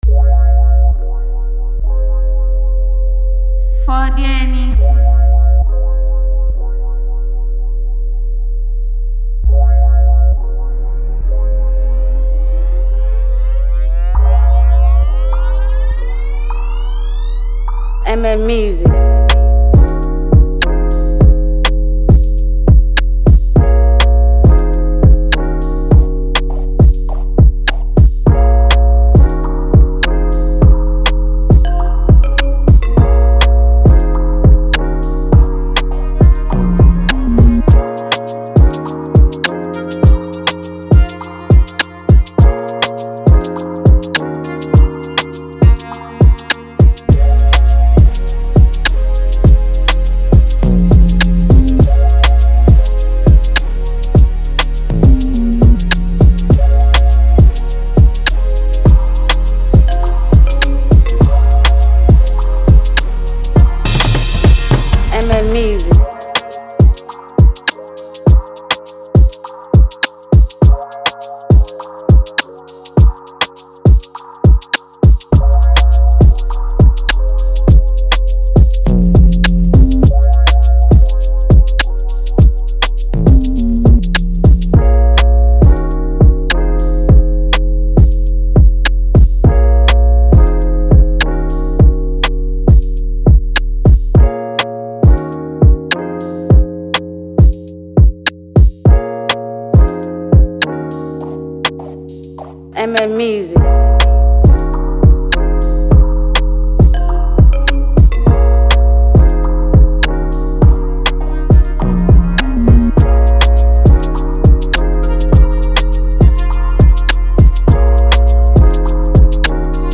With its catchy hooks and uplifting vibe